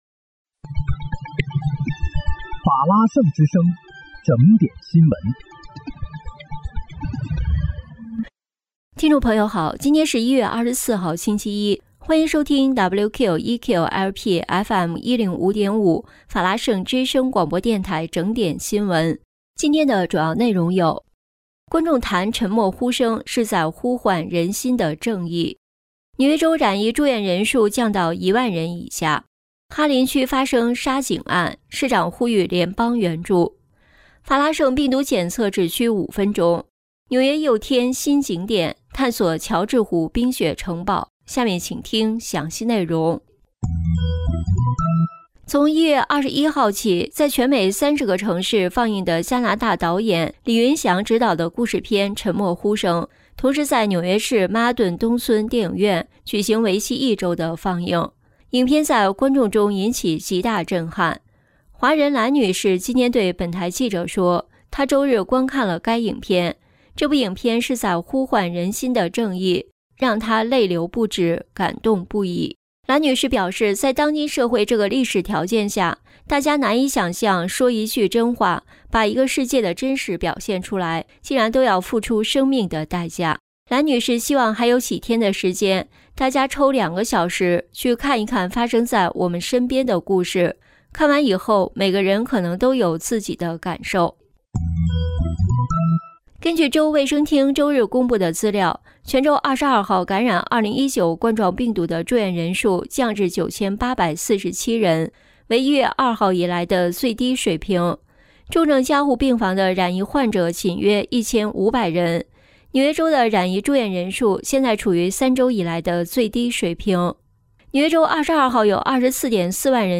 1月24日（星期一）纽约整点新闻